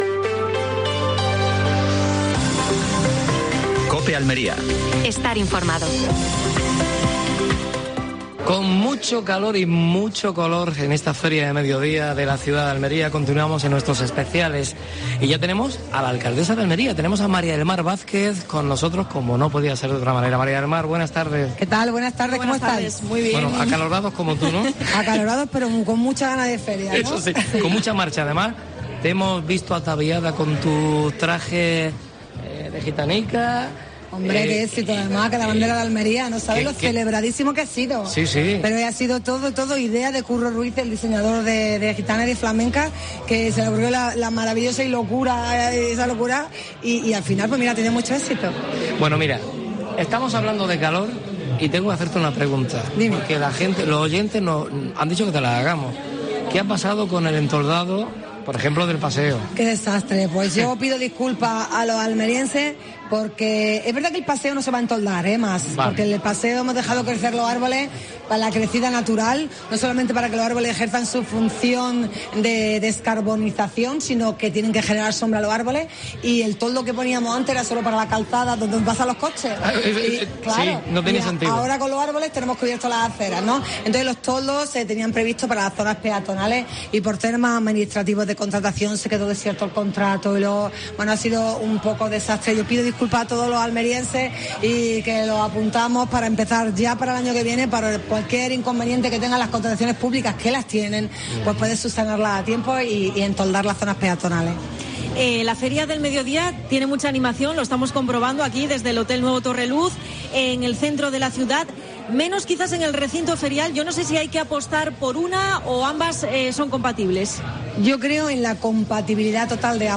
Última hora en Almería. Programa especial de la Feria de Almería. Desde el Hotel Torreluz. Entrevista a María del Mar Vázquez (alcaldesa de Almería).